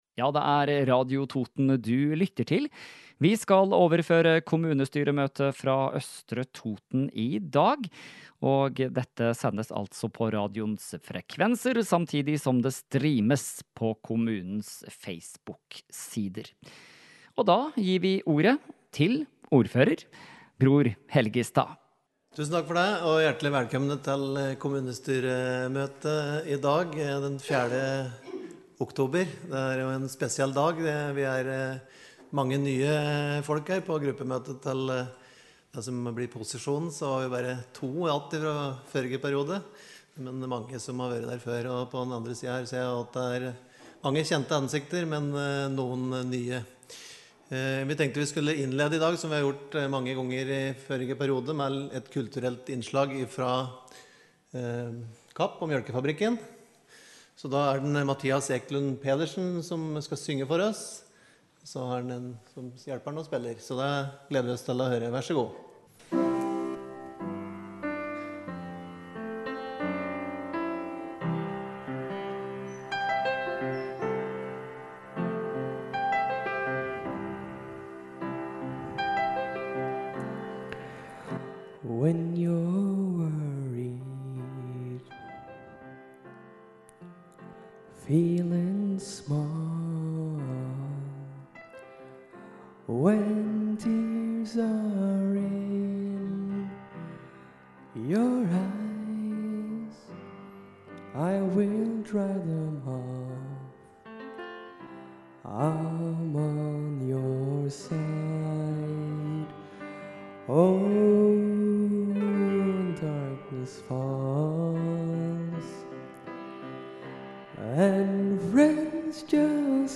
Kommunestyremøte fra Østre Toten 4. oktober – Lydfiler lagt ut | Radio Toten